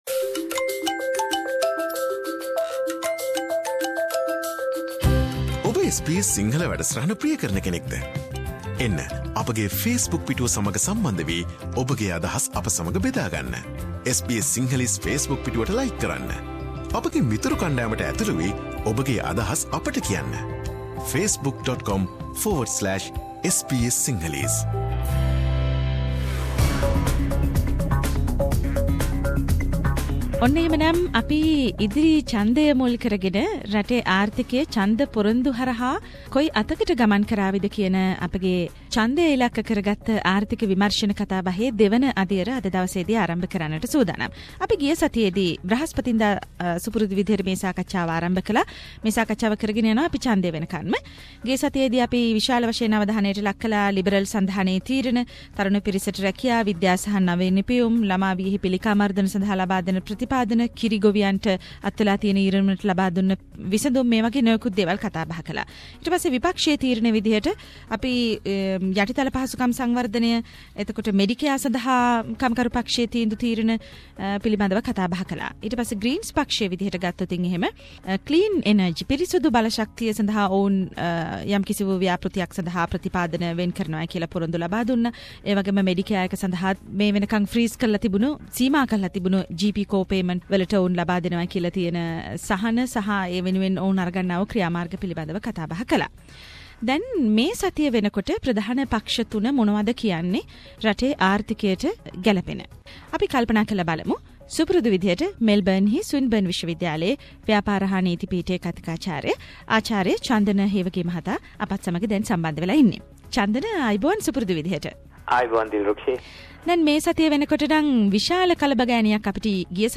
ඉදිරි ඕස්ට්‍රේලියානු මහමැතිවරණය ඉලක්කකොට ඕස්ට්‍රේලියාවේ ප්‍රධාන දේශපාලන පක්ෂ විසින් එක් එක් සතියේදී ඉදිරිපත්කරන කැපී පෙනෙන දේශපාලන පොරොන්දු රටේ ආර්ථිකයට කෙලෙසින් බලපාවිද යන්න පිළිබඳව මැතිවරණය තෙක් SBS සිංහල වැඩසටහන ගෙන එන විශේෂ "ආර්ථික විමර්ශන" සාකච්චා මාලාවේ දෙවැන්න තුලින් මෙවර අවධානයට ලක්වනුයේ…